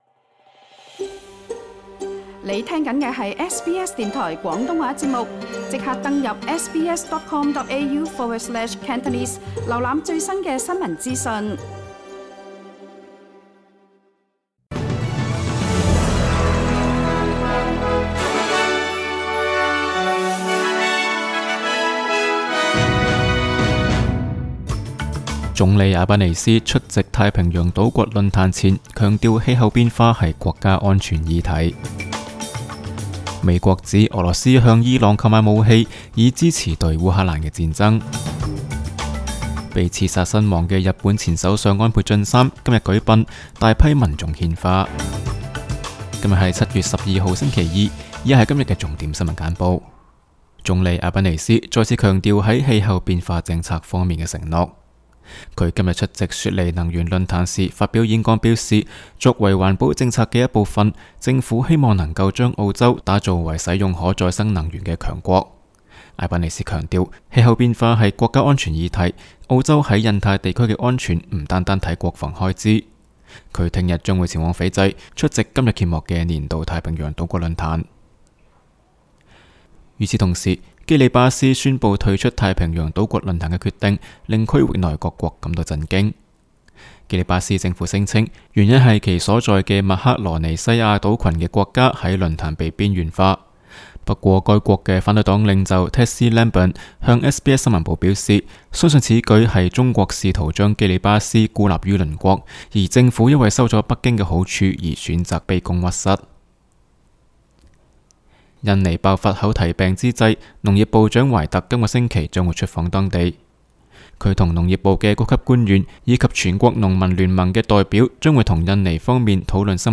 SBS 新闻简报（7月12日）
SBS 廣東話節目新聞簡報 Source: SBS Cantonese